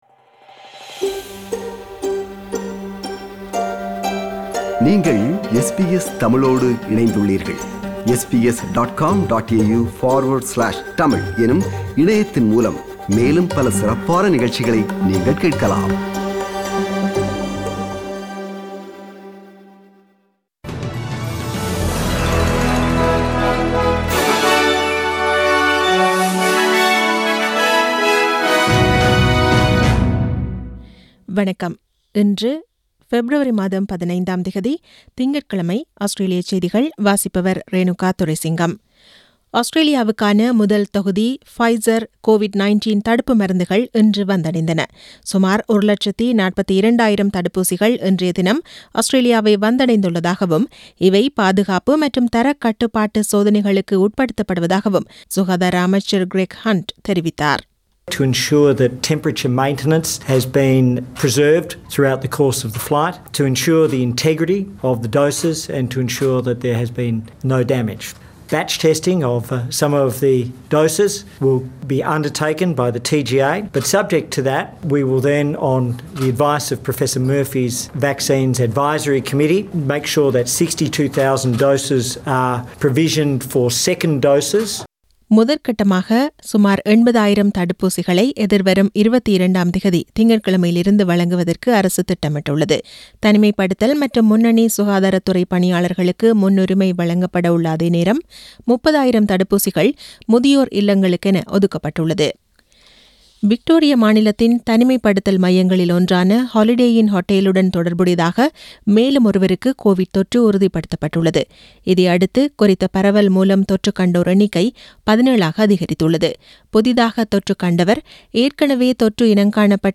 Australian News: 15 February 2021 – Monday